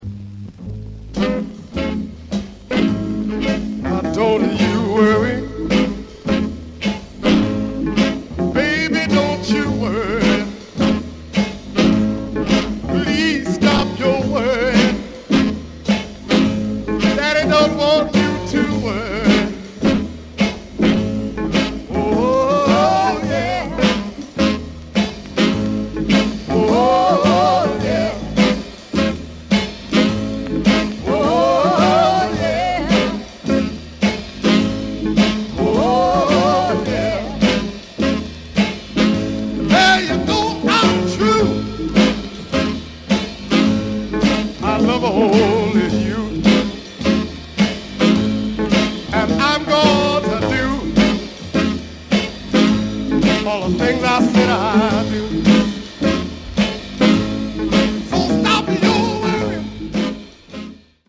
R&B stroller